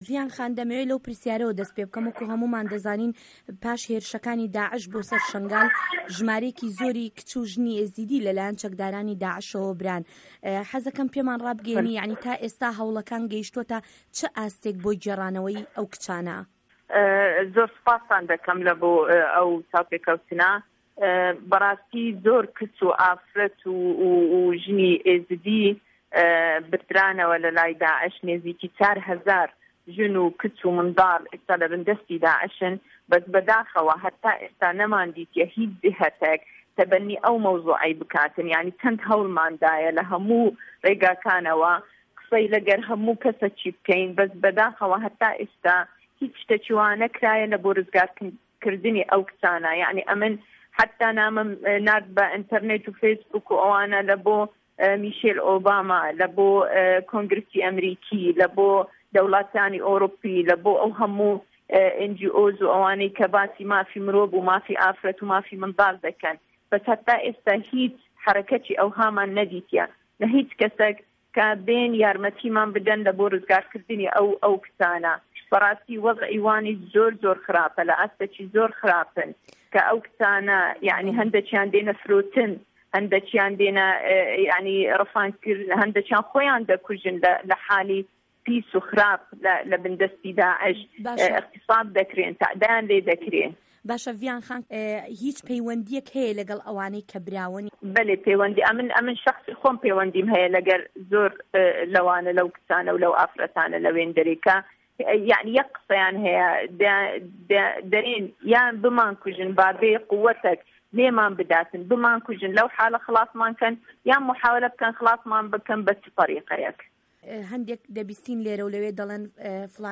وتووێژ له‌گه‌ڵ ڤیان ده‌خیل